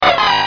Sounds / Cries